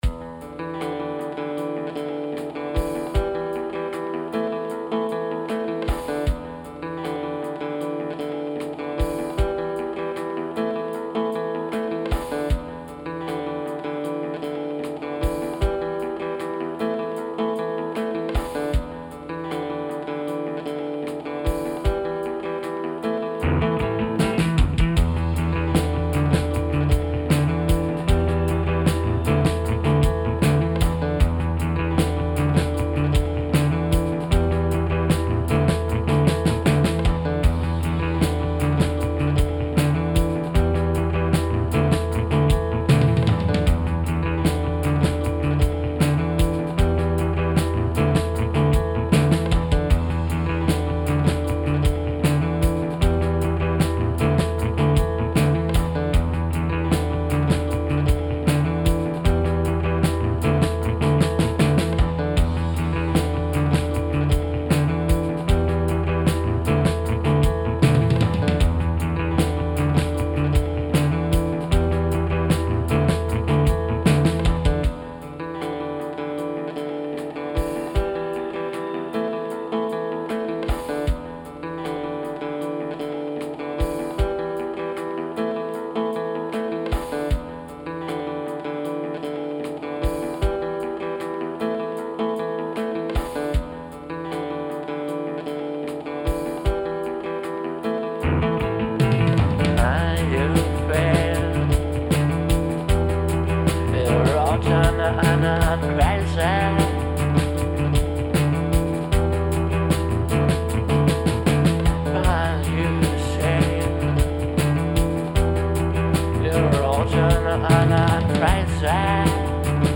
Вот набросал что то простенькое, эксперементальное Вся реверберация Микроверб 3 Подключение в режиме send\stereo пресет Lrg Room- 9